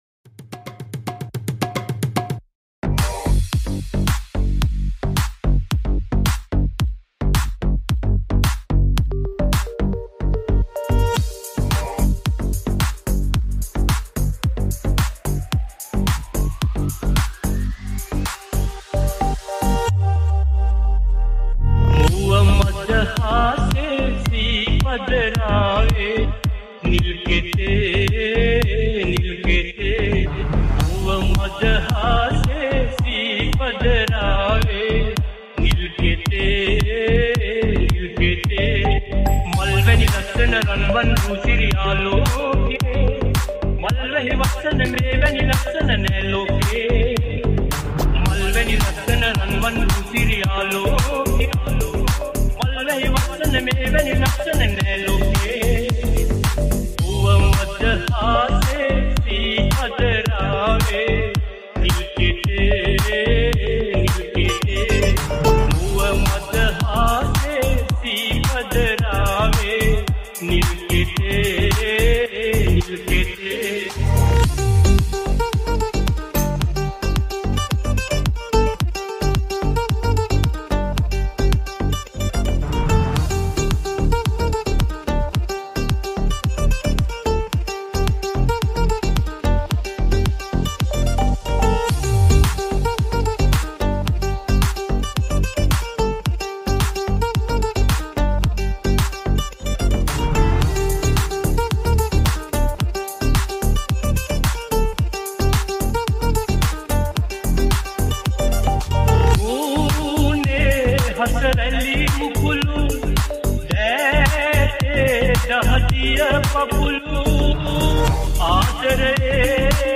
Bootleg Remix Mp3 Download